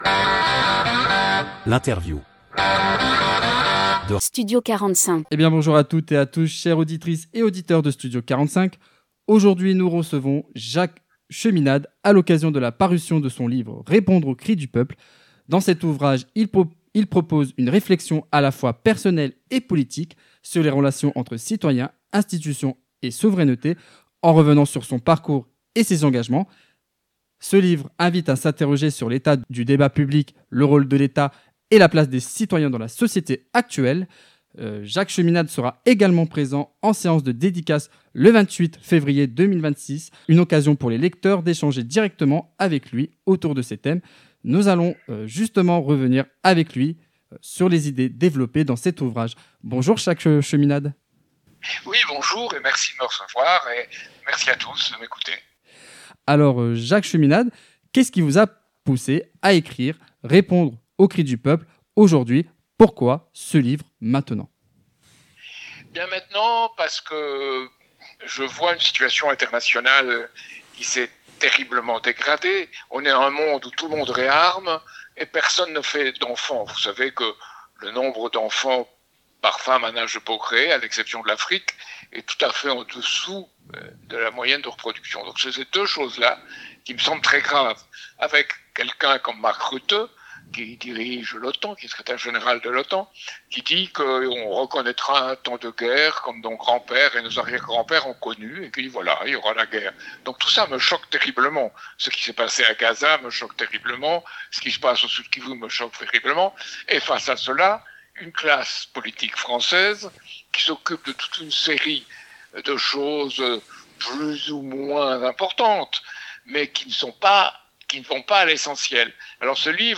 Interview Studio 45 - Jacques Cheminade